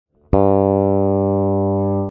Cuerda primera del bajo: SOL (G)
La primera cuerda del bajo, la más aguda, está afinada en SOL.
cuerda-sol-al-aire.mp3